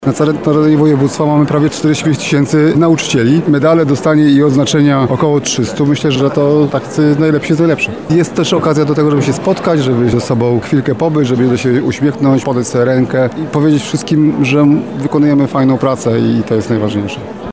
Dzisiaj (20.10) w Sali Błękitnej Urzędu Wojewódzkiego zebrali się przedstawiciele i przedstawicielki naszej oświaty. Tym, którzy wyróżnili się swoimi działaniami, wręczono nagrody Ministra i Kuratora, a także odznaczenia państwowe i resortowe.
Tomasz Szabłowski – mówi Tomasz Szabłowski, Lubelski Kurator Oświaty.